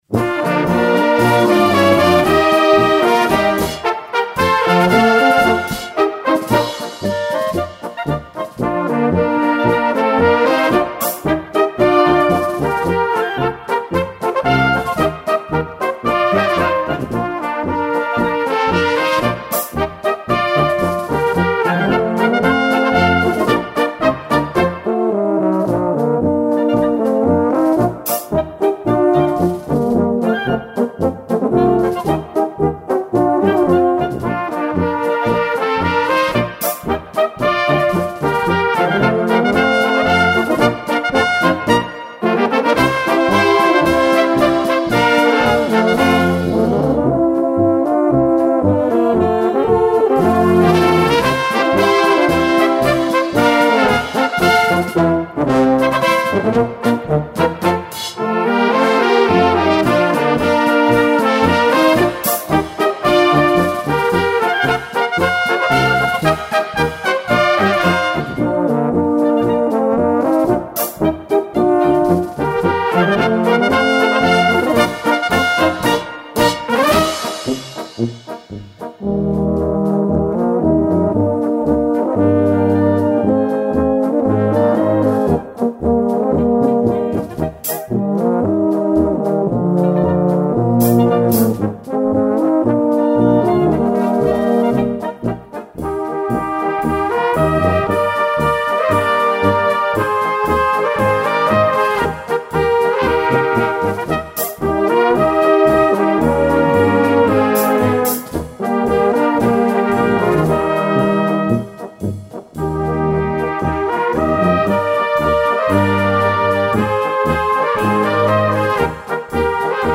brass band
plays polkas and waltzes in the Bohemian style. 6th album.